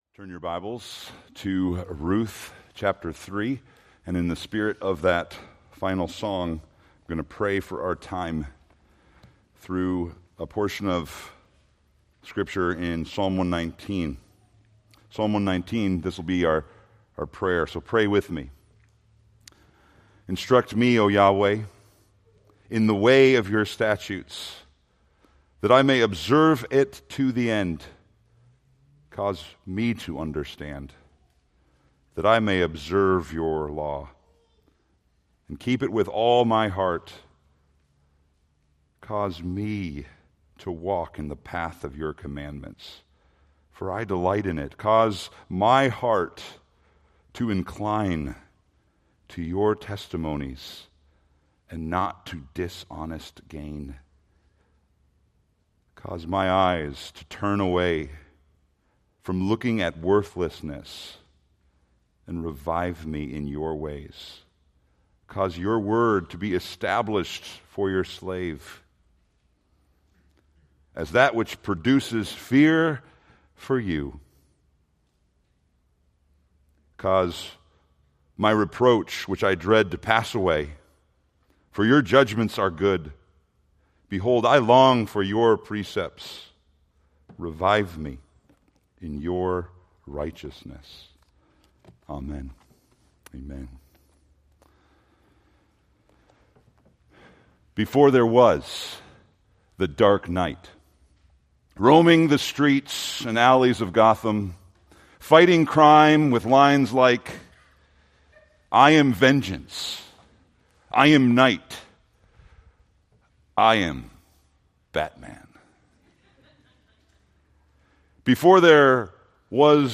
Preached May 25, 2025 from Ruth 3-4